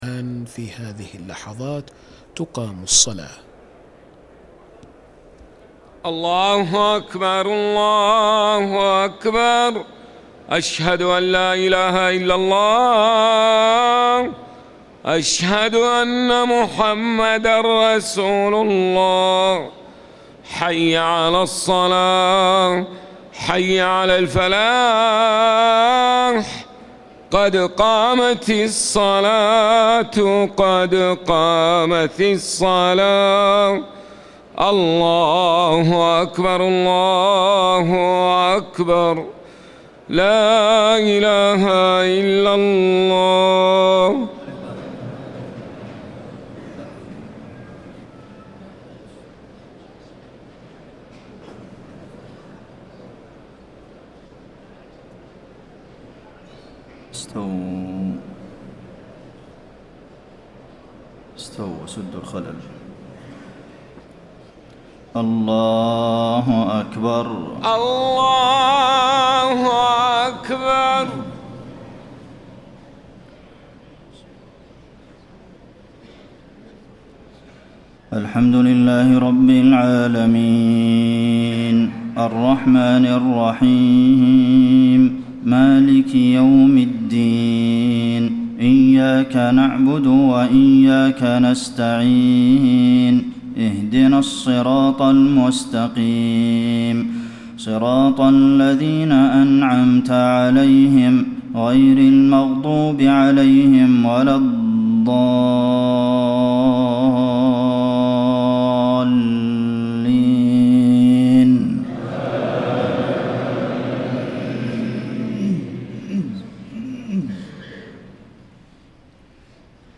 صلاة العشاء 1-8-1434 من سورة النساء > 1434 🕌 > الفروض - تلاوات الحرمين